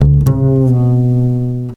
DoubleBass 7 F.wav